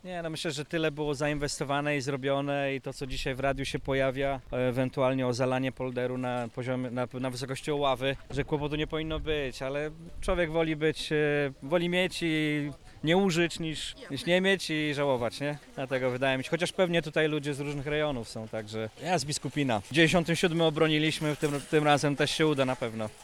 Spotkaliśmy tam kilku mieszkańców Biskupina. Jeden z nich uważa, że woda, która nadejdzie nie powinna wyrządzić wielkich szkód.
mieszkaniec-Biskupina_obronia-osiedle.mp3